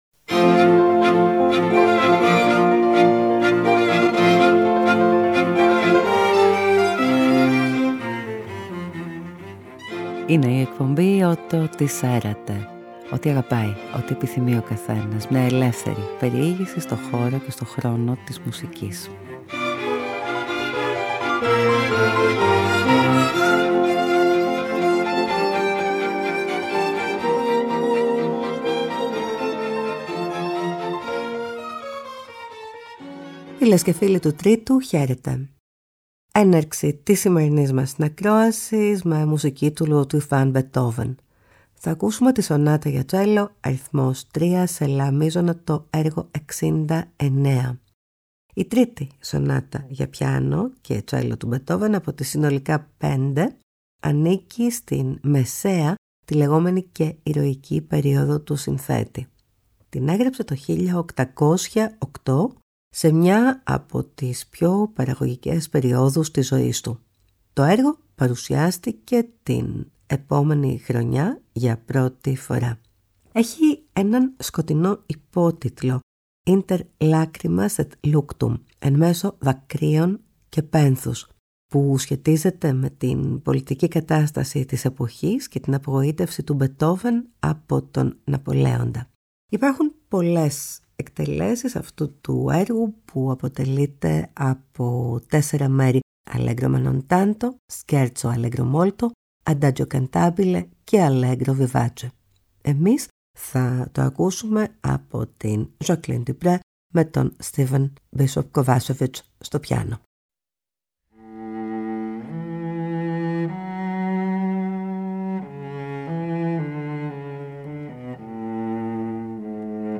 Ludwig Van Beethoven Σονάτα για τσέλλο σε Λα Μείζονα, αρ. 3 οπ. 69Gaetano Donizetti Κουαρτέτο αρ 10 σε σολ ελάσσοναFelix Mendelssohn Ήρεμη θάλασσα και Αίσιο ταξίδι